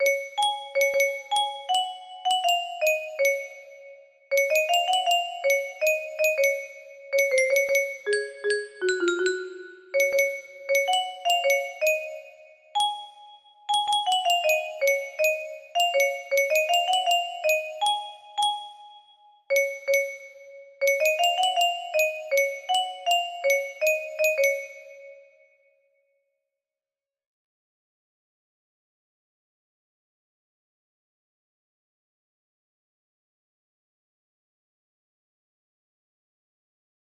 code: C# major